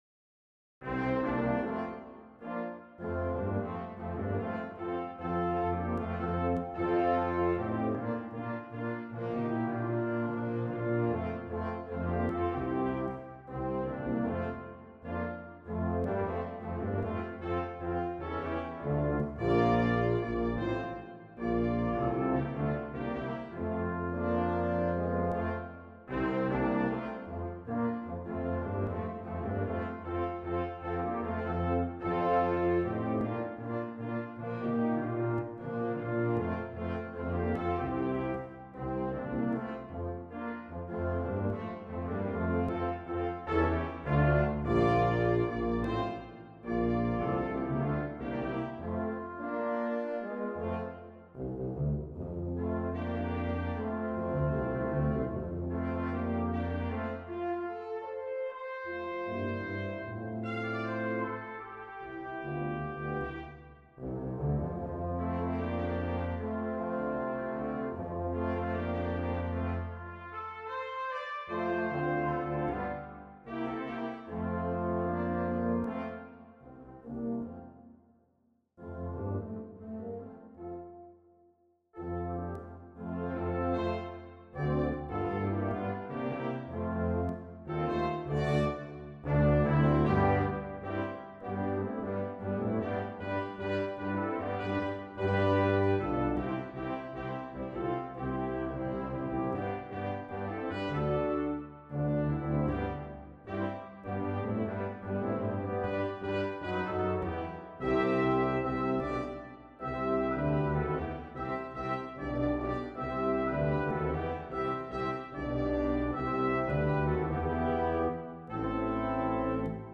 Trumpet,Horn,2 Trombones,Tuba